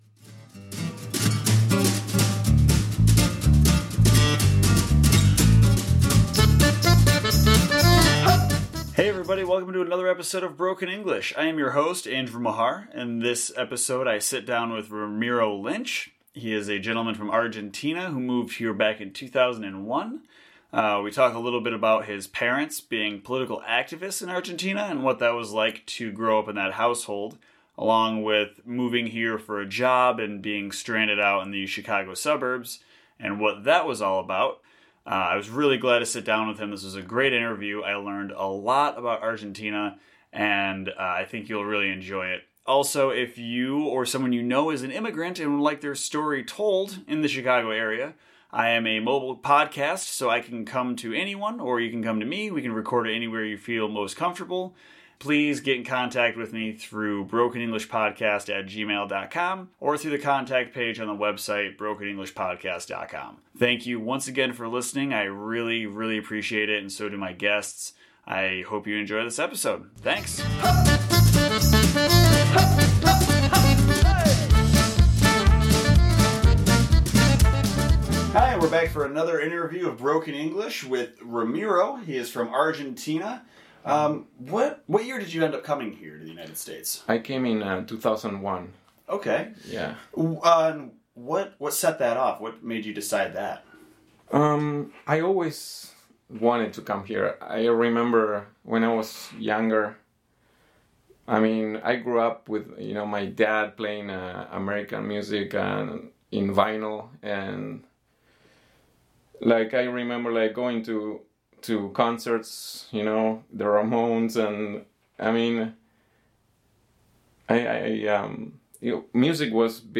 This week we’ve got an excellent interview